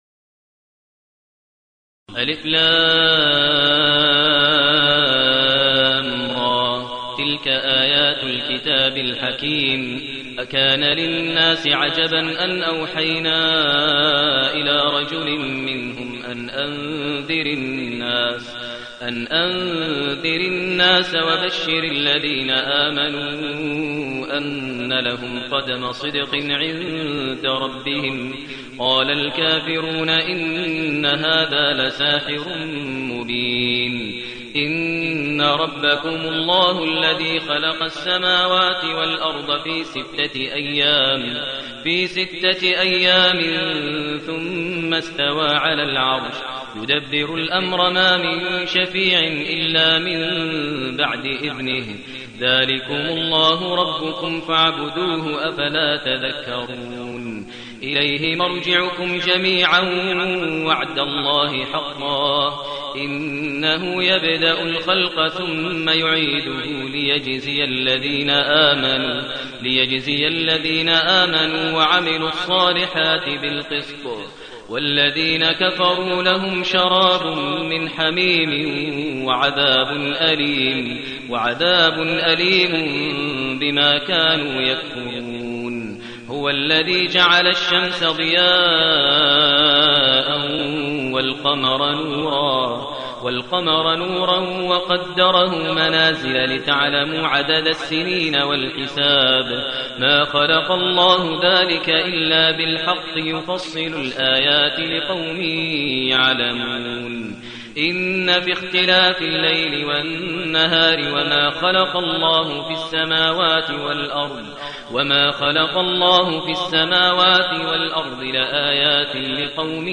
المكان: المسجد النبوي الشيخ: فضيلة الشيخ ماهر المعيقلي فضيلة الشيخ ماهر المعيقلي يونس